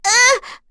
Erze-Vox_Damage_03.wav